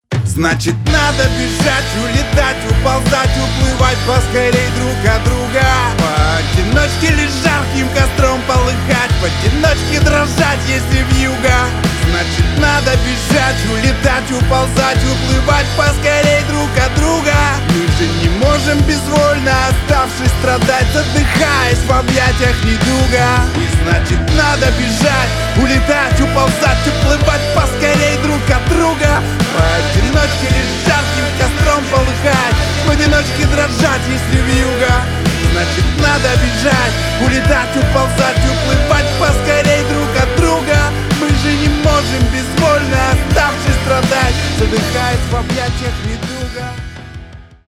рэп , рок